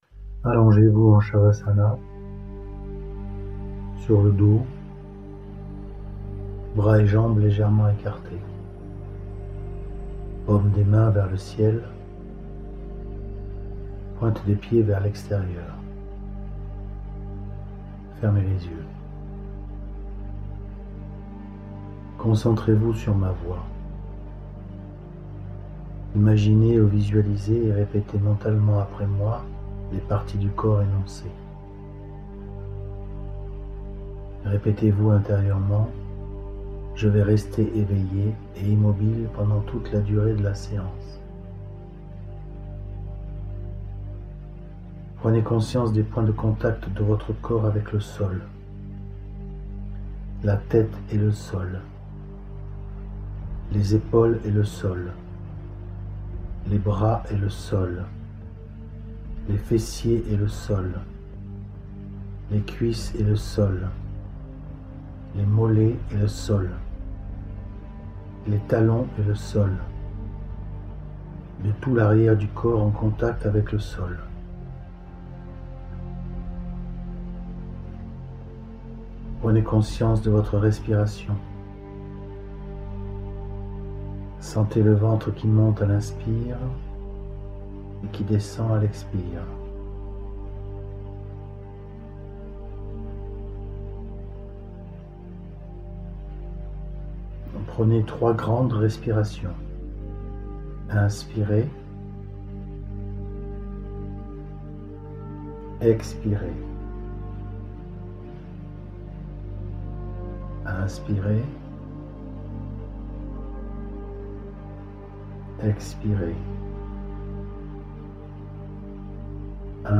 Télécharger ce yoga nidra (relaxation)